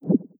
now-playing-pop-out.wav